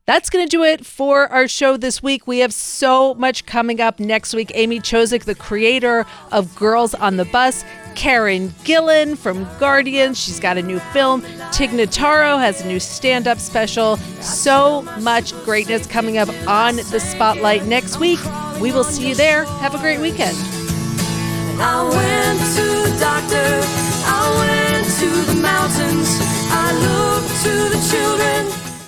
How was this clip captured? (captured from webcast)